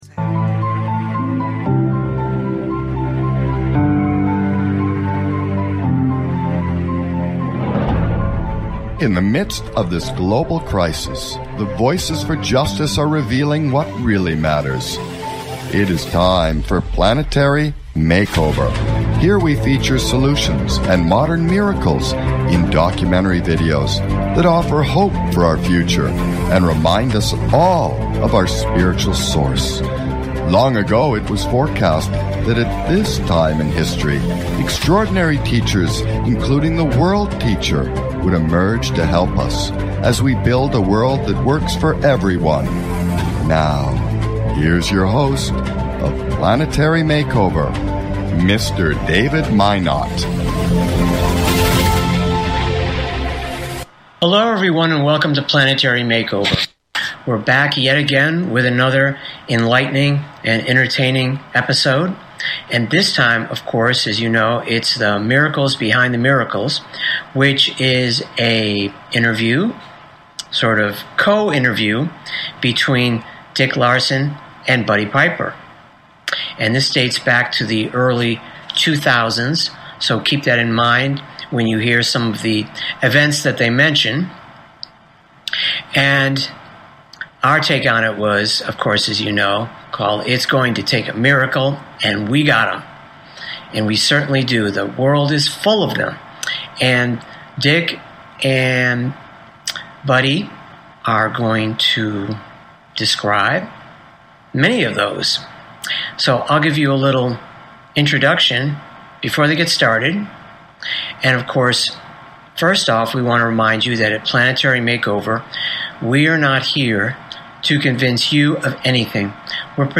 Talk Show Episode, Audio Podcast, Planetary Makeover Show and It’s Going to Take a Miracle?